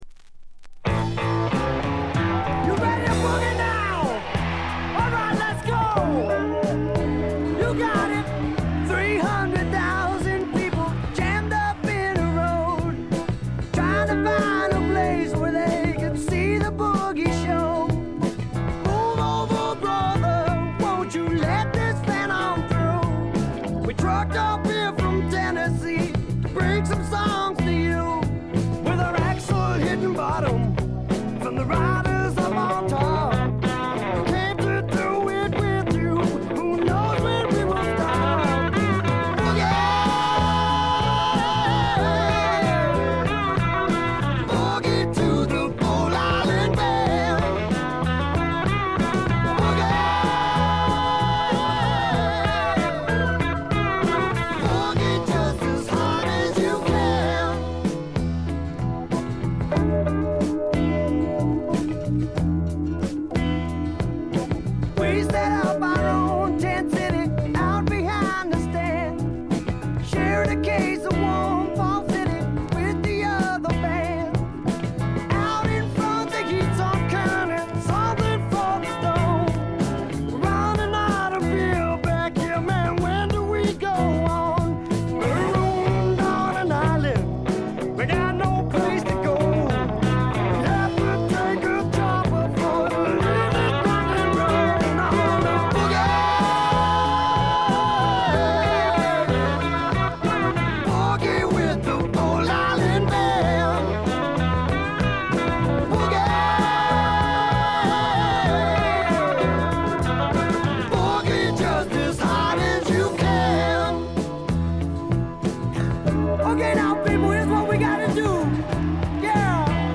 軽微なチリプチ少し。
ずばりスワンプ名作！
試聴曲は現品からの取り込み音源です。